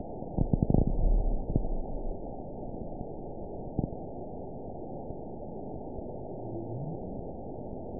event 921585 date 12/05/24 time 14:50:25 GMT (10 months, 3 weeks ago) score 9.16 location TSS-AB04 detected by nrw target species NRW annotations +NRW Spectrogram: Frequency (kHz) vs. Time (s) audio not available .wav